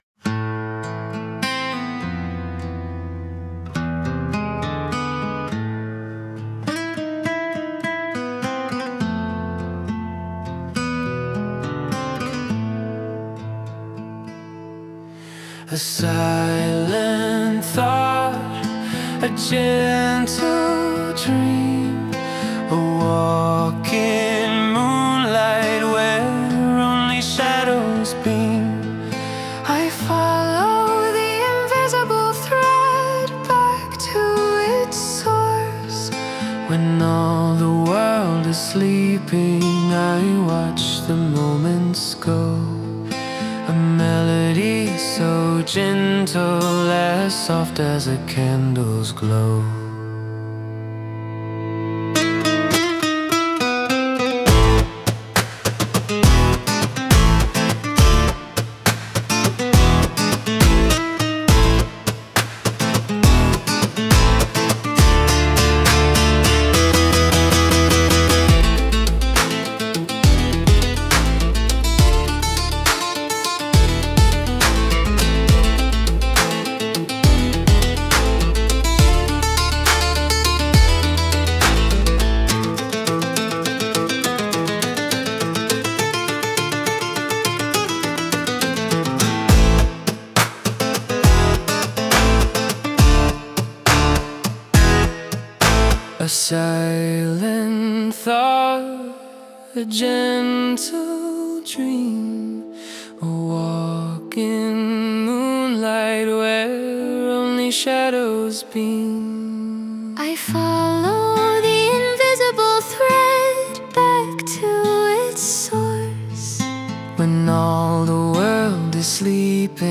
Für-Elise(Acoustic-Version).wav